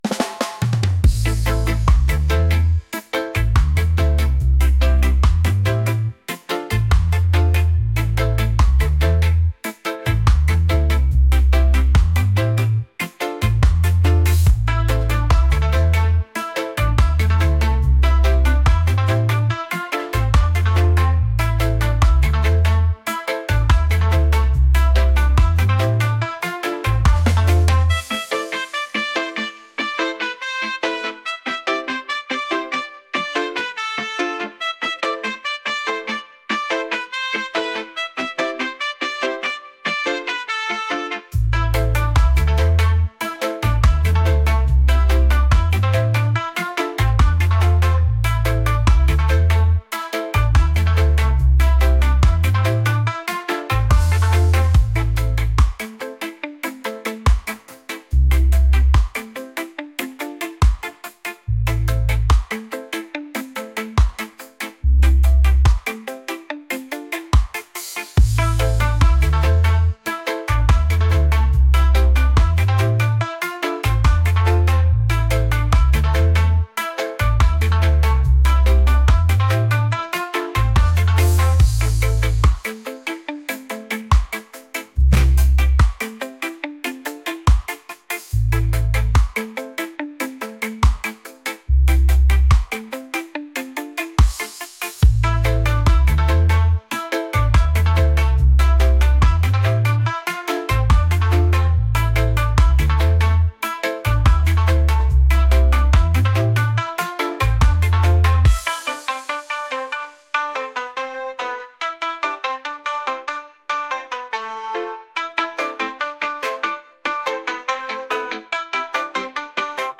reggae | funk | pop